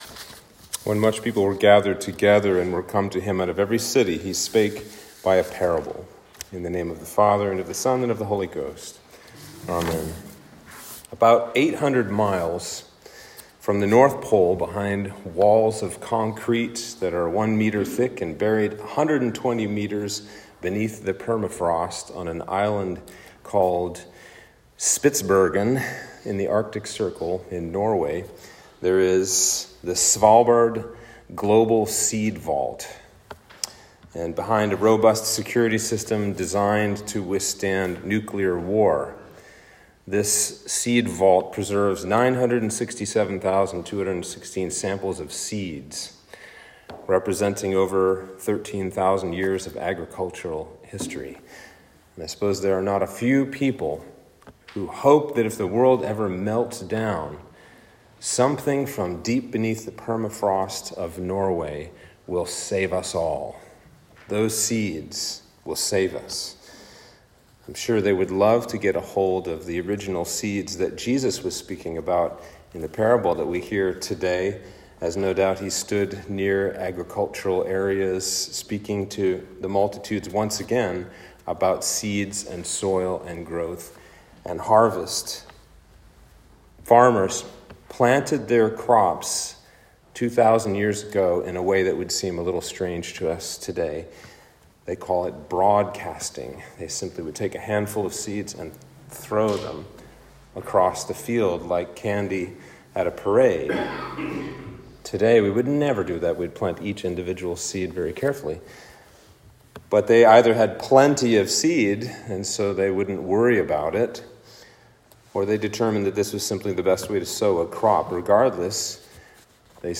Sermon for Sexagesima Sunday